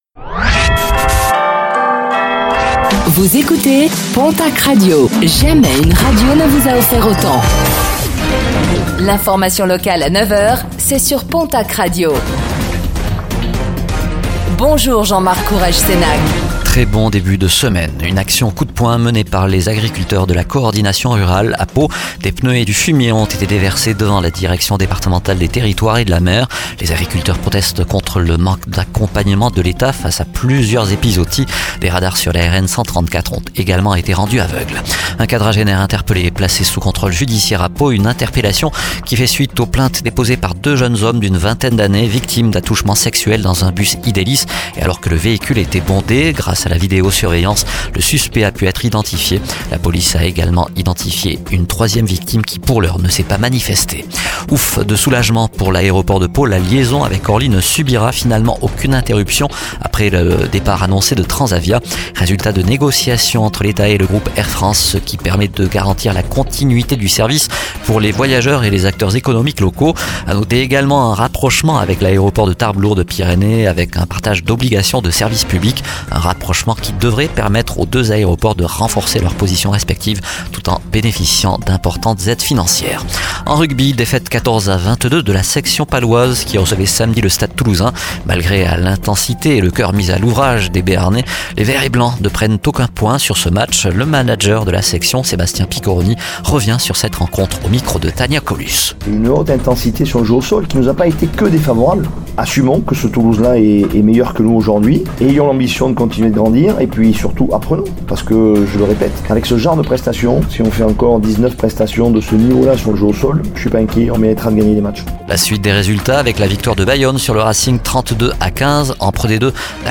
Infos | Lundi 21 octobre 2024